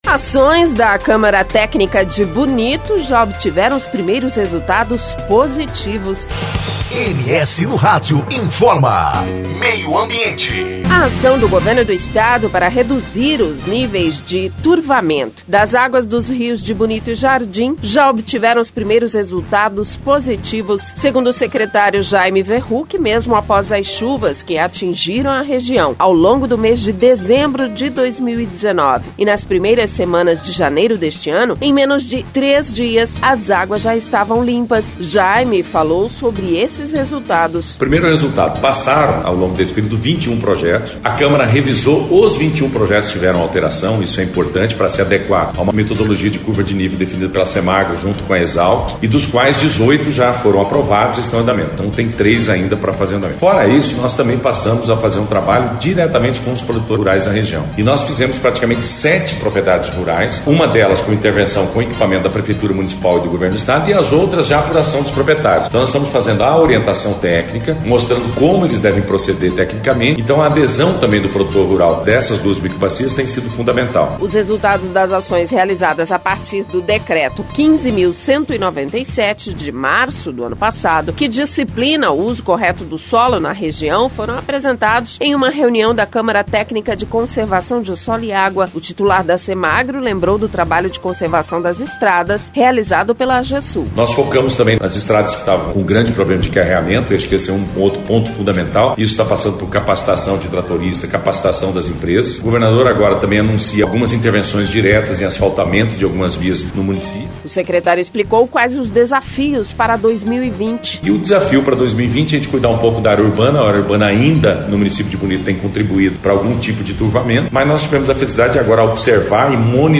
Jaime falou sobre esses resultados.
O Secretário explicou quais os desafios para 2020.